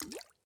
drip_water_cauldron2.ogg